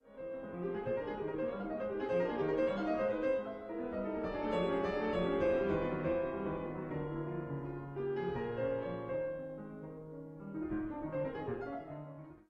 I det følende eksempel hører du en kromatisk følge av sekstakkorder, som alle er i dur.